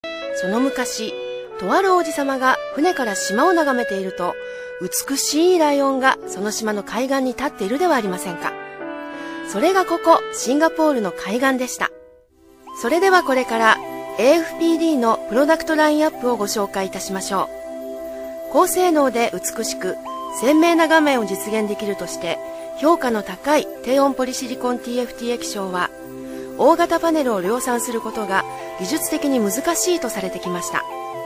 Sprecherin japanisch für TV / Rundfunk / Industrie.
Sprechprobe: Industrie (Muttersprache):
Professionell female voice over artist from Japan.